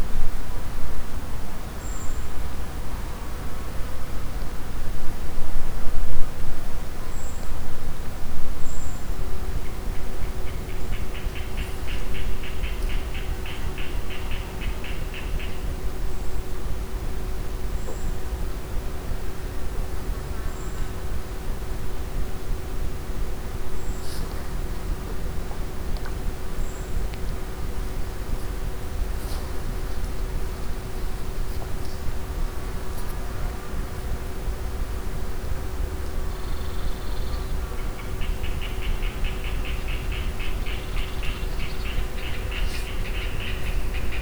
ambientOutdoorNoise.wav